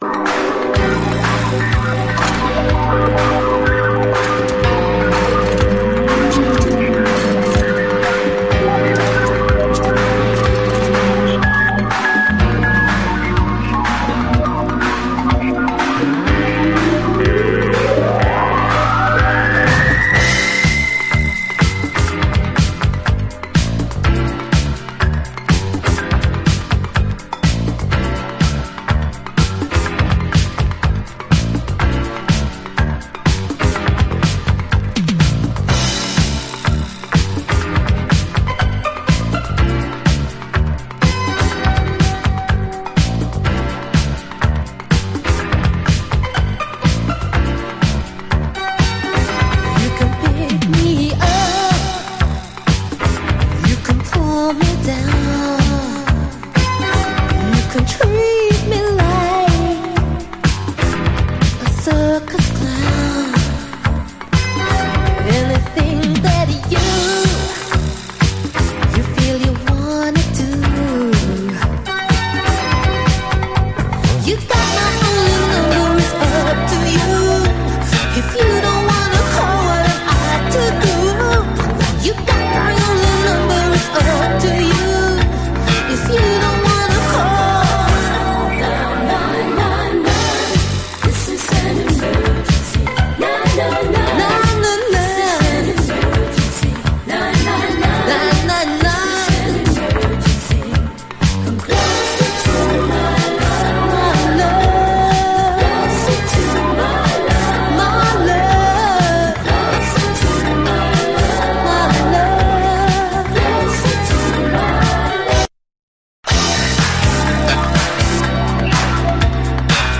このうっすら聴こえるシンセが気持ちいい！